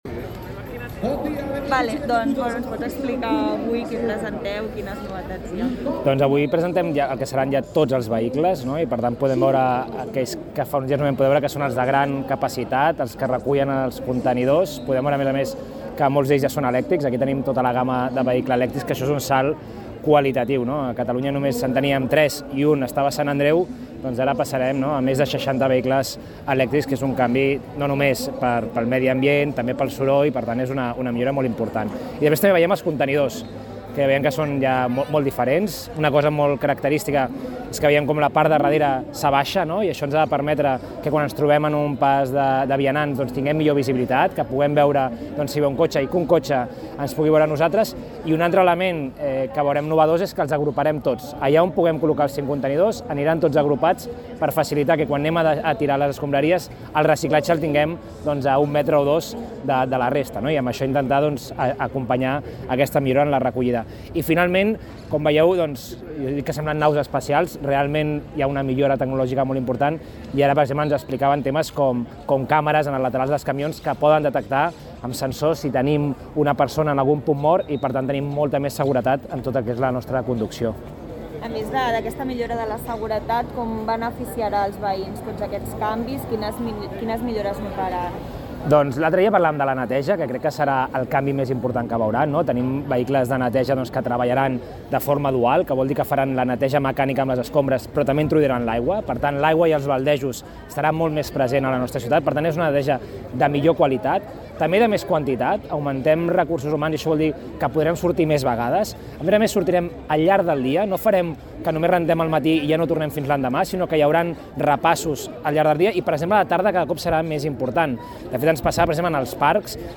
Declaracions d’Eloi Badia